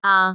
speech
syllable
pronunciation